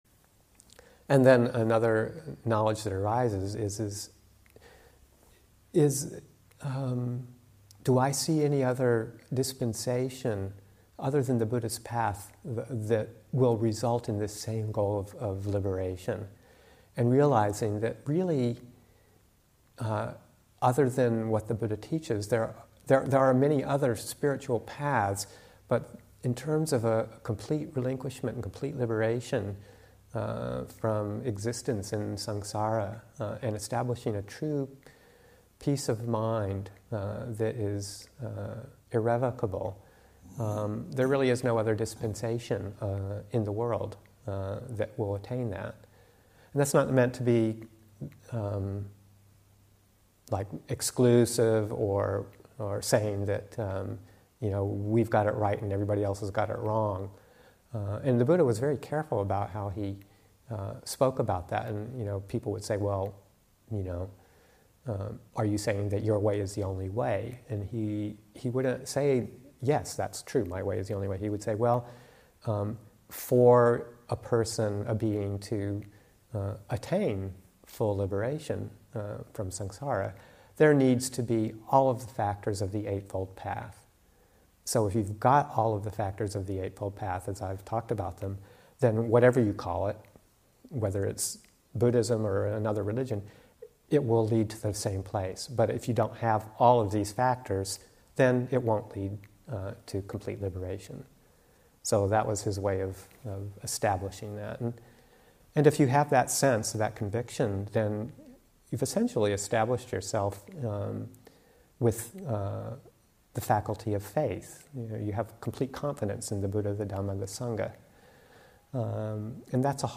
12. Reviewing knowledge #3: Complete confidence in the Buddha, Dhamma and Saṅgha. Teaching
Abhayagiri 25th Anniversary Retreat, Session 22 – Jun. 15, 2021